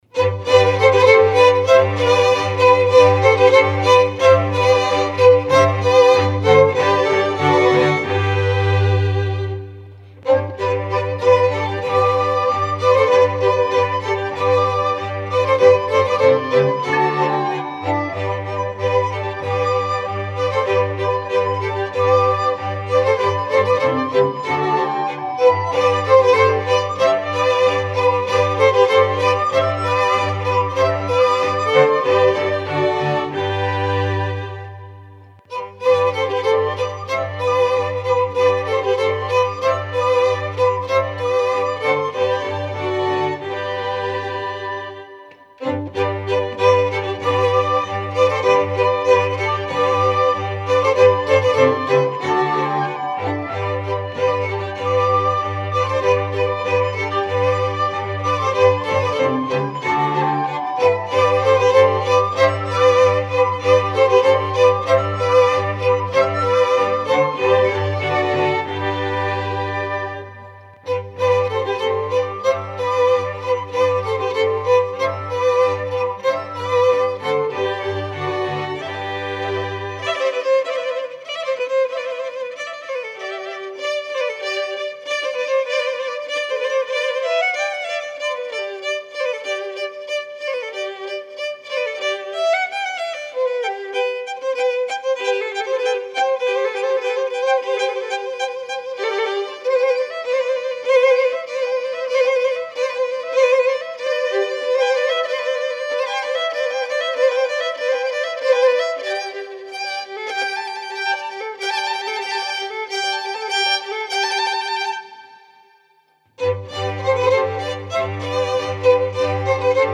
flautas